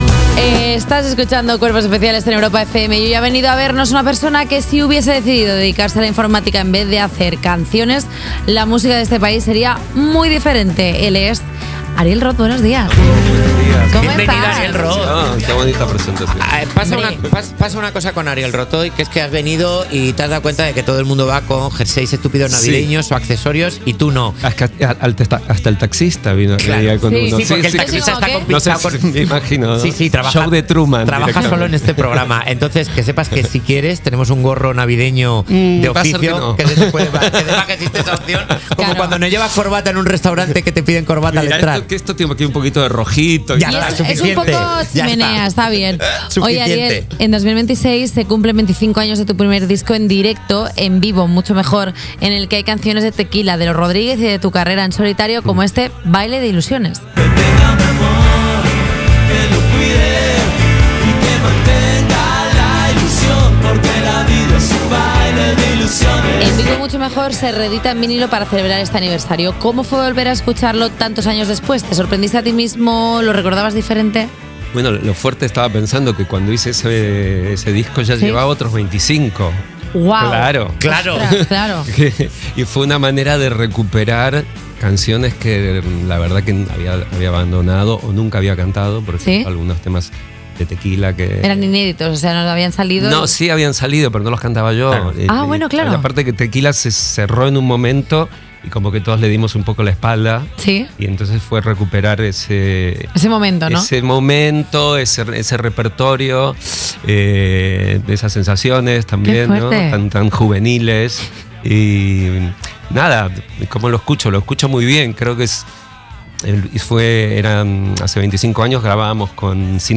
La entrevista de Ariel Rot en Cuerpos especiales